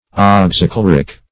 Search Result for " oxychloric" : The Collaborative International Dictionary of English v.0.48: Oxychloric \Ox`y*chlo"ric\, a. [Oxy (a) + chloric.]